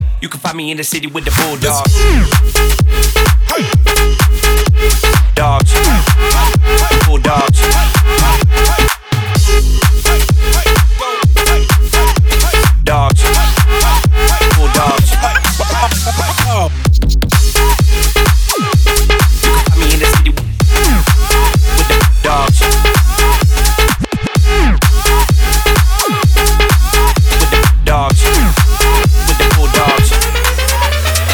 мужской голос
громкие
EDM
мощные басы
future house
качающие
electro house